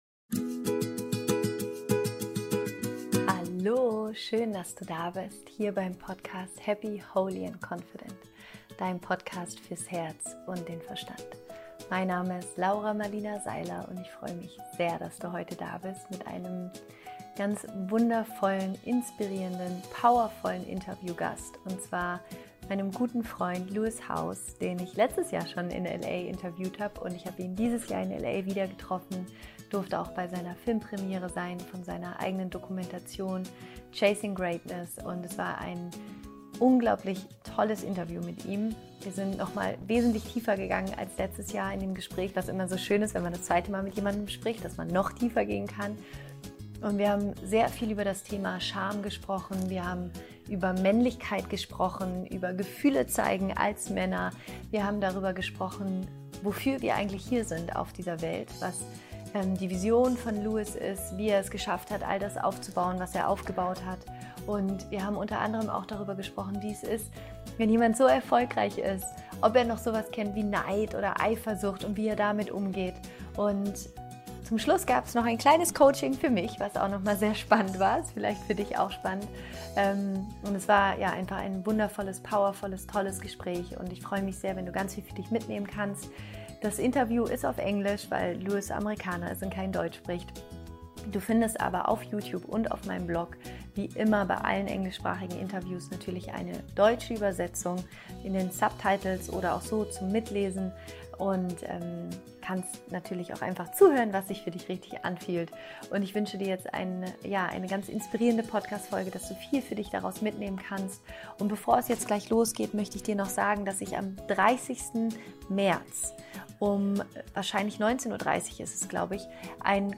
Befreie dich von alten Geschichten – Interview Special mit Lewis Howes